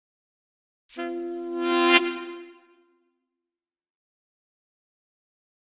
Saxophone
Horn Section Criminal Increasing 2 - Light